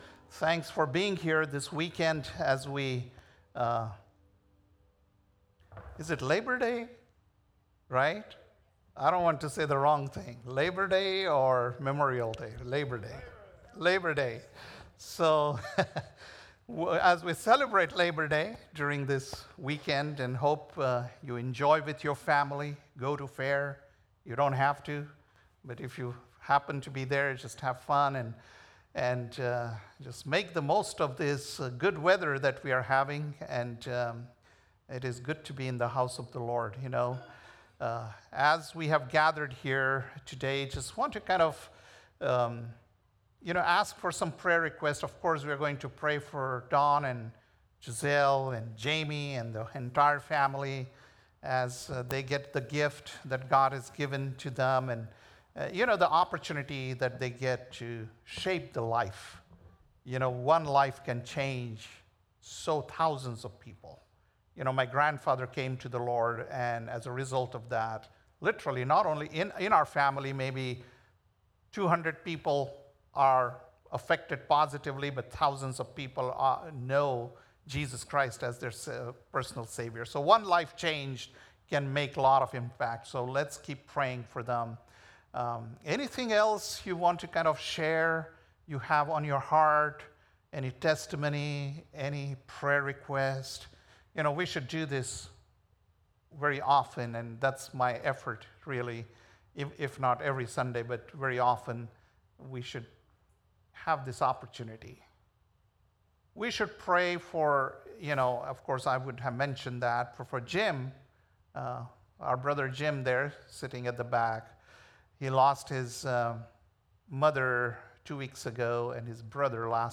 August 31st, 2025 - Sunday Service - Wasilla Lake Church